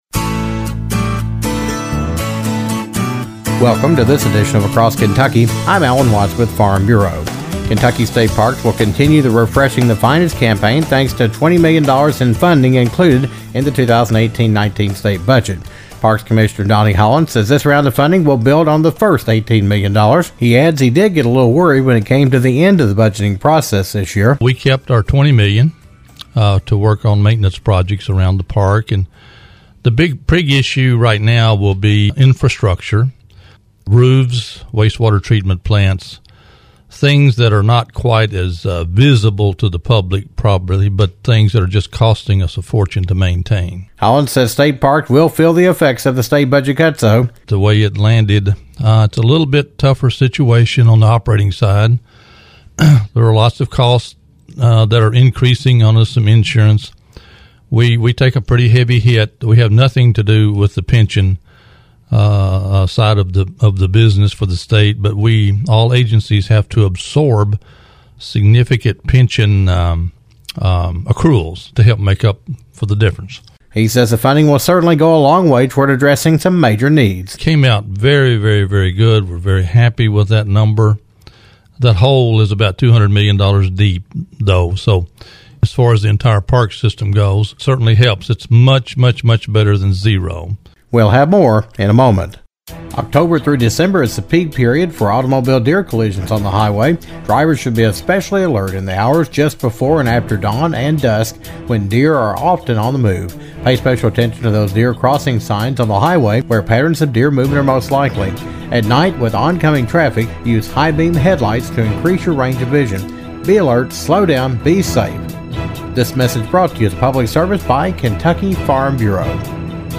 The Kentucky Parks system will continue to undergo major renovations thanks to $20 million in funding in the state budget that was passed in April. Parks Commissioner Donnie Holland talks about the improvements, the effects on the number of visitors at state parks and how that is affecting the bottom line.